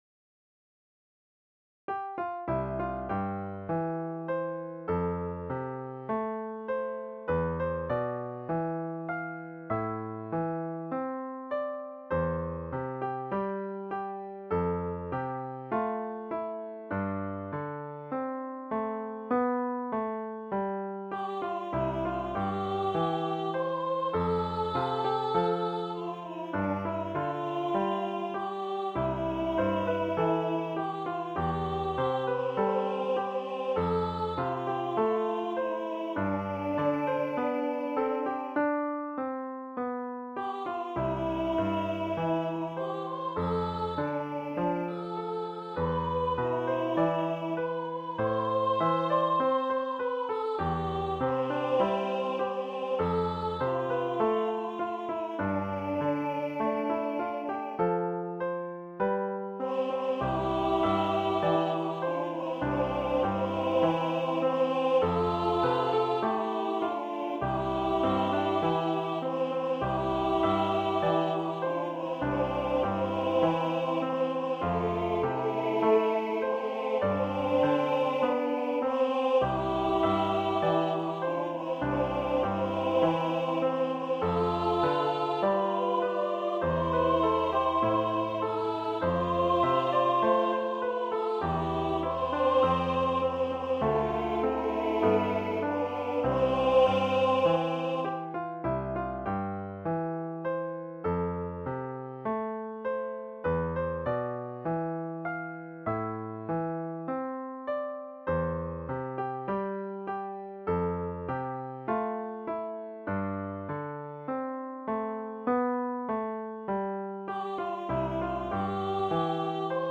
2 part choir
Young Women Voices
The melody and harmony parts are simple and the accompaniment is easy enough for an early intermediate player. Included is an optional violin obligato for one, or two violins.
EFY style/Contemporary